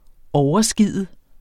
Udtale [ ˈɒwʌˌsgiˀðə ]